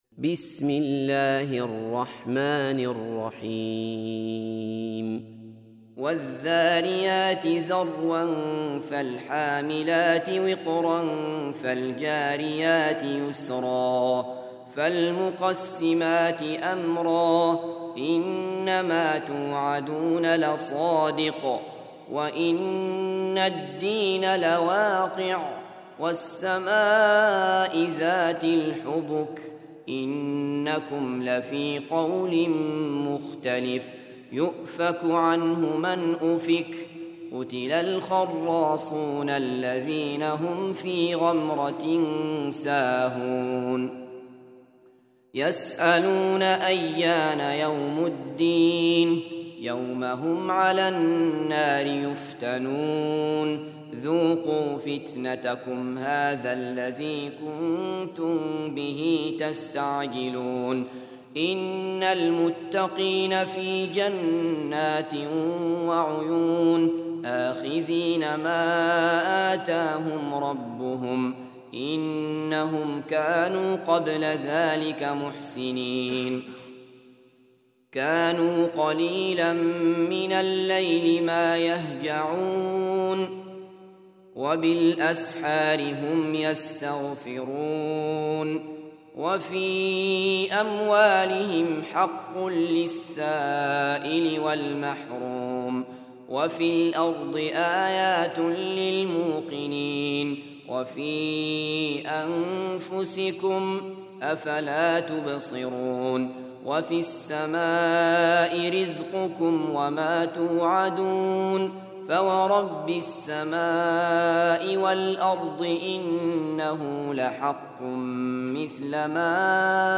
51. Surah Az-Z�riy�t سورة الذاريات Audio Quran Tarteel Recitation
Surah Repeating تكرار السورة Download Surah حمّل السورة Reciting Murattalah Audio for 51.